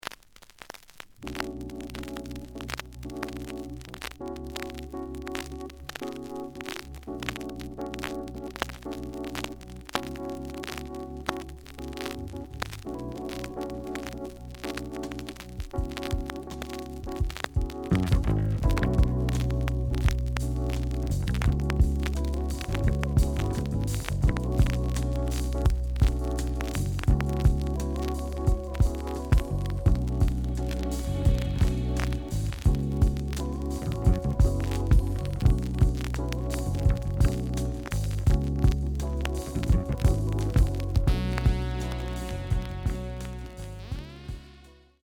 The audio sample is recorded from the actual item.
●Genre: Funk, 70's Funk
Some noise on B side.